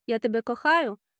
ya TEH-beh ko-KHAH-yoo Romantic love only
The letter Х makes a soft, breathy sound - like the "ch" in Scottish "loch" or German "Bach."